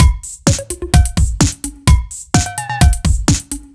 Index of /musicradar/french-house-chillout-samples/128bpm/Beats